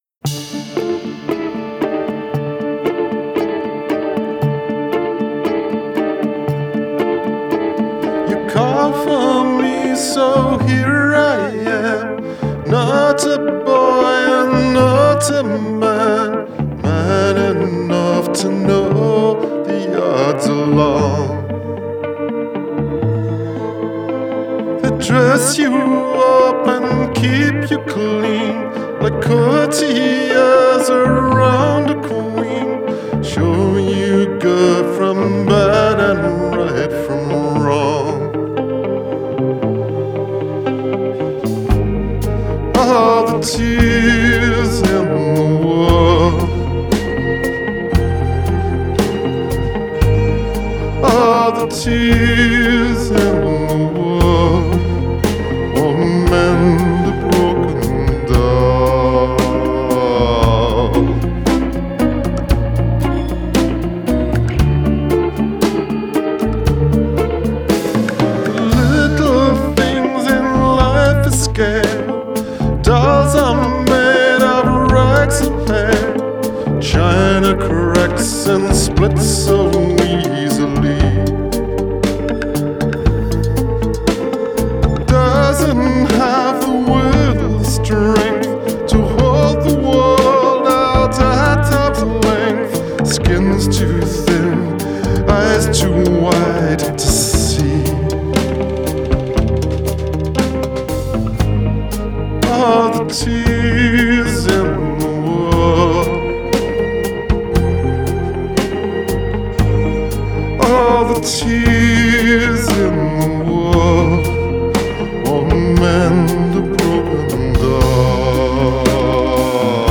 Genre: Folk, Singer-Songwriter